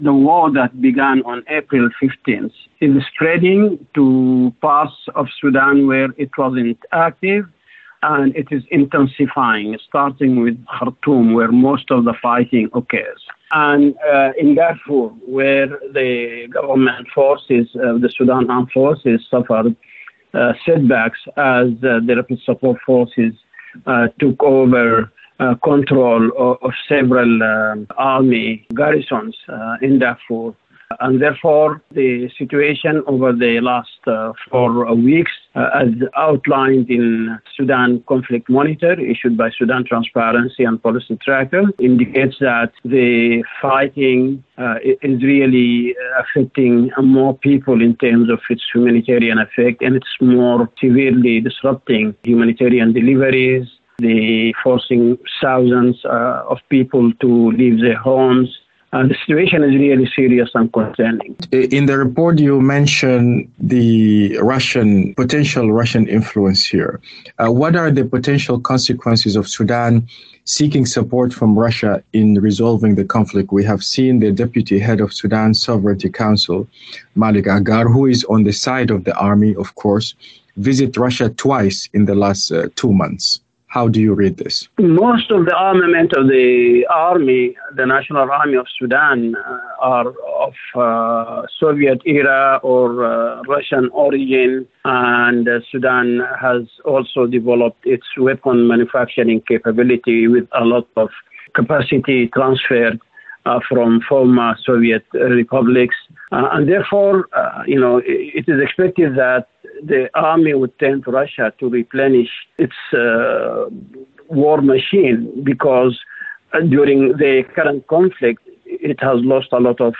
The conflict between Sudan’s army and the paramilitary Rapid Support Forces has been spreading and intensifying 15 weeks after its eruption, according to a report released today by the think tank Sudan Transparency and Policy Tracker. In an interview